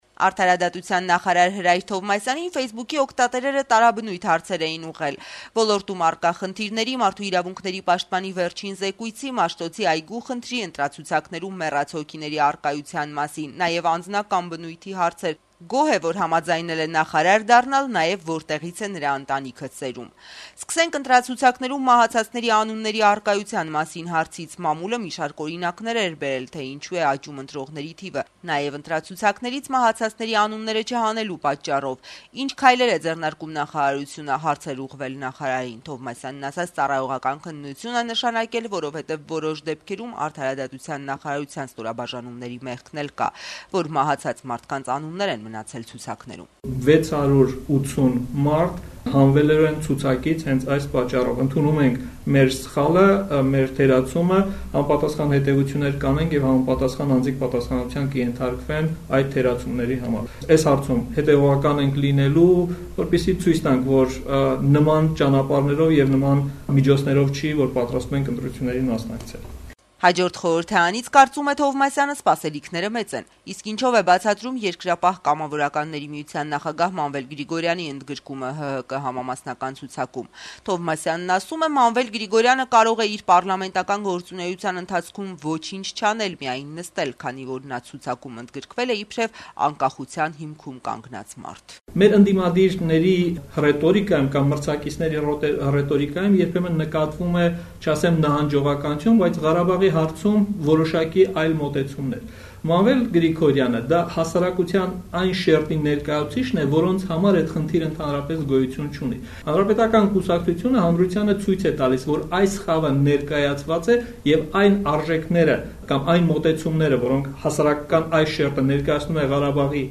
Արդարադատության նախարարի ասուլիսը «Ազատության» եթերում
Հրայր Թովմասյանը ուղիղ եթերում պատասխանեց Ֆեյսբուք սոցիալական ցանցի օգտատերերի հարցերին։